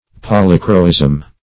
Polychroism \Pol"y*chro*ism\, n.
polychroism.mp3